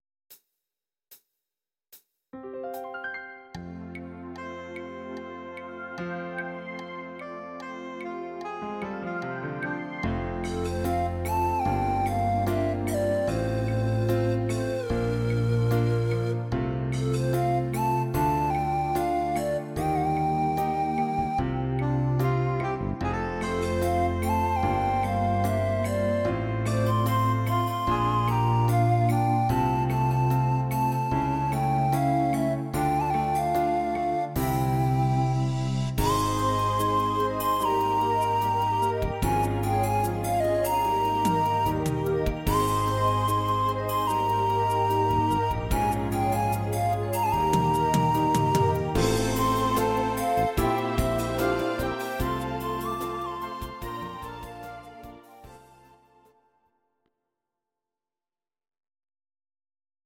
Audio Recordings based on Midi-files
German, 1990s